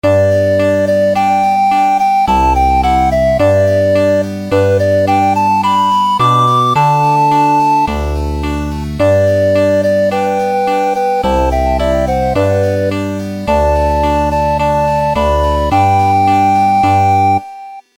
リコーダー